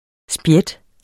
Udtale [ ˈsbjεd ]